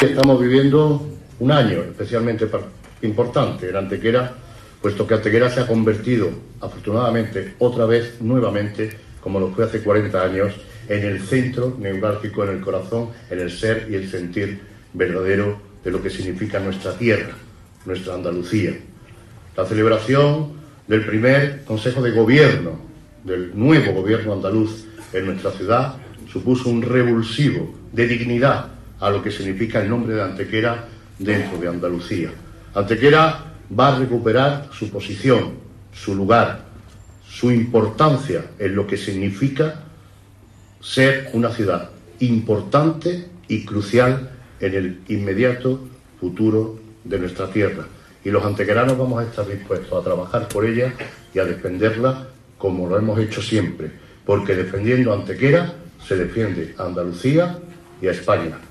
Extracto del discurso del Alcalde de Antequera en la entrega de los Premios Efebo en el 28-F
Generar Pdf viernes 1 de marzo de 2019 Extracto del discurso del Alcalde de Antequera en la entrega de los Premios Efebo en el 28-F Generar Pdf AUDIO Corte de audio con un extracto del discurso del Alcalde de Antequera en la entrega de los Premios Efebo celebrada este jueves 28 de febrero en el Teatro Torcal de Antequera conmemorando el Día de Andalucía.